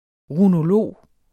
Udtale [ ʁunoˈloˀ ]